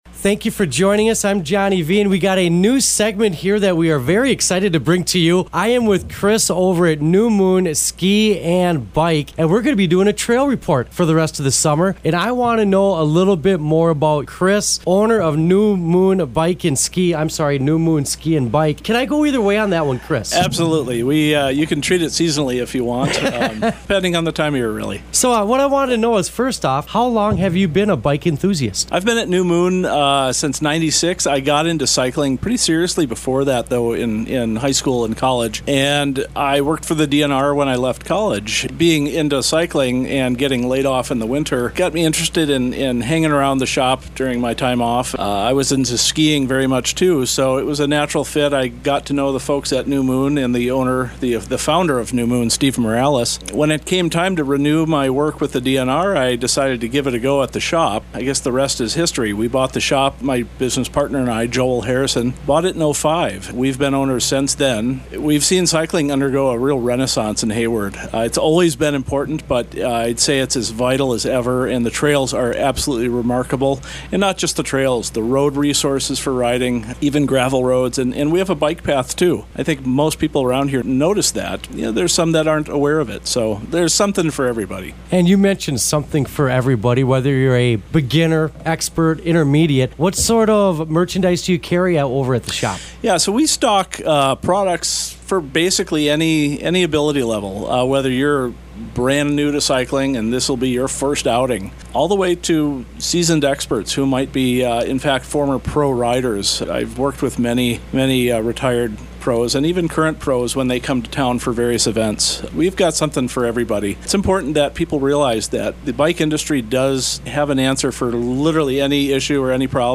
Our most recent outreach project is the Silent Sports Trail Report, a short radio show on two of Hayward’s local radio stations. WHSM Muskie Country, 101.1 and The Cabin, 96.9 will be airing the interview based report Friday and Saturday around 8:30am.